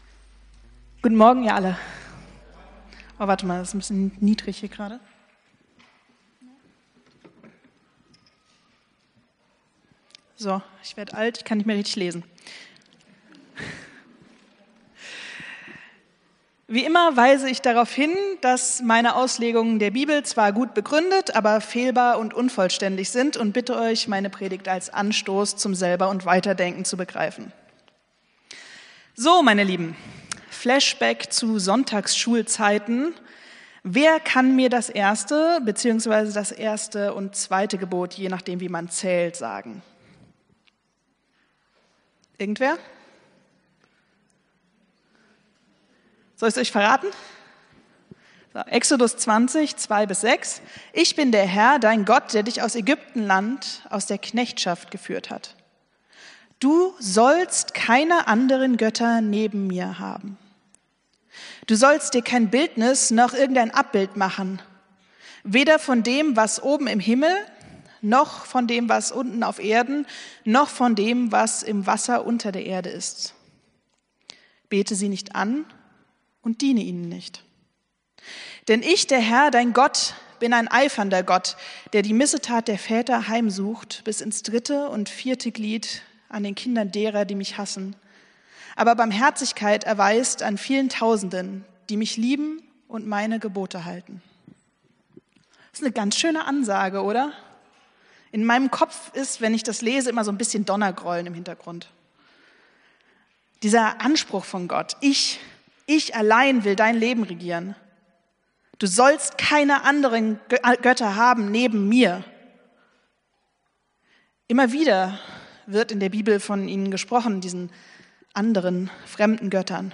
Predigt vom 30.03.2025